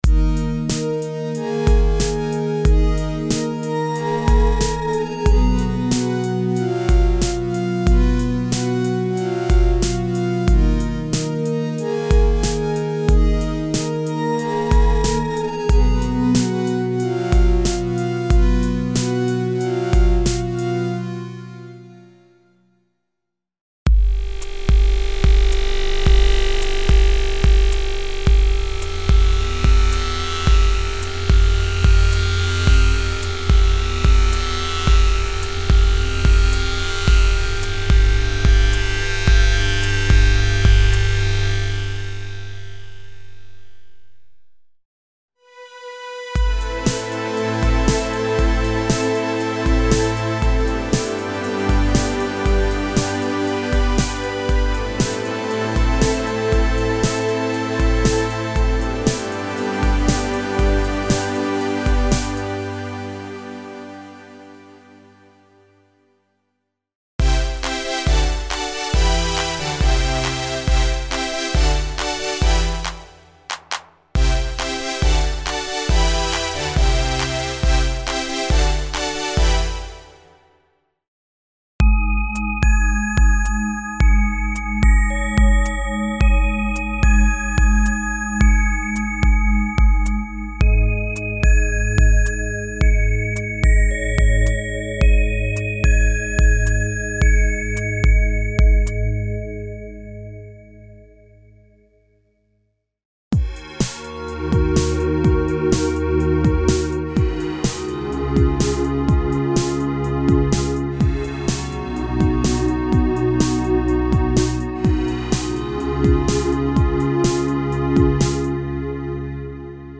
Emulations of vintage digital synthesizers - PPG, DX (FM synthesis) program variations (experimental and "ice-cold" digital pads).
Info: All original K:Works sound programs use internal Kurzweil K2500 ROM samples exclusively, there are no external samples used.